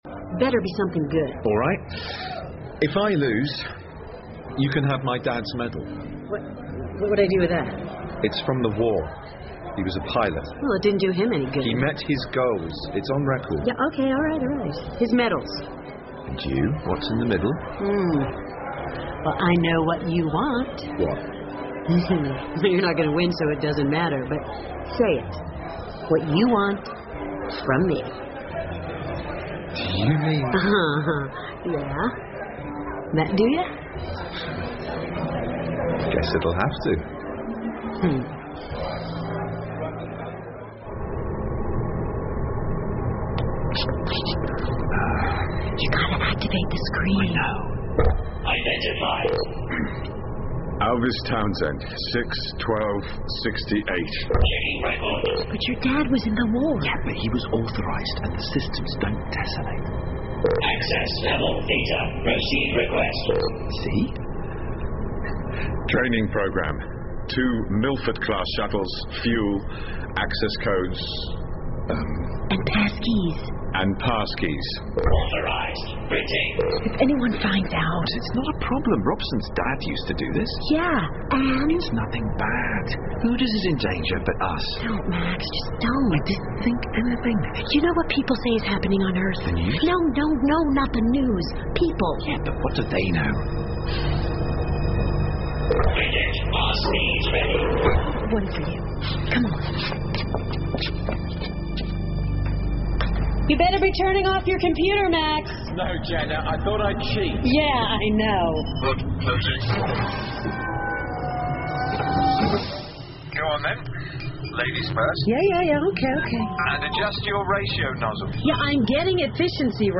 英文广播剧在线听 Benedict Cumberbatch 08 听力文件下载—在线英语听力室